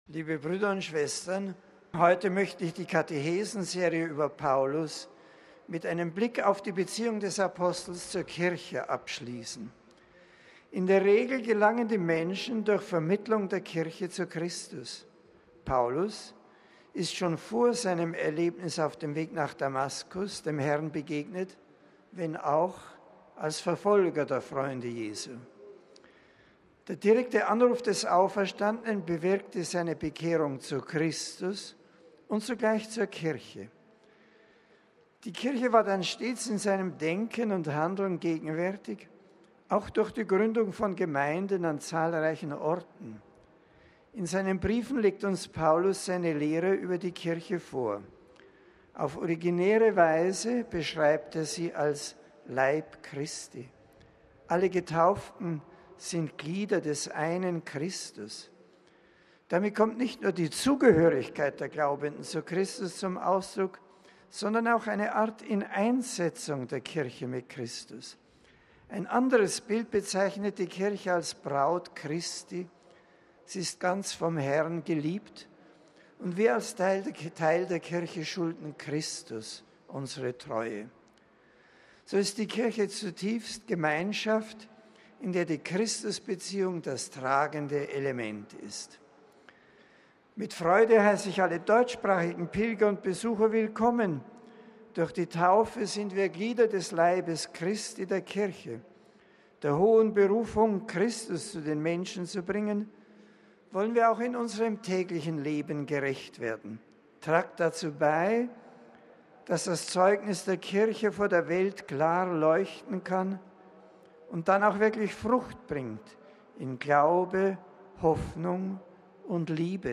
Generalaudienz: Paulus und die Kirche
MP3 Papst Benedikt hat die Reihe seiner Mittwochskatechesen über den Völkerapostel Paulus abgeschlossen. Auf Deutsch sagte er: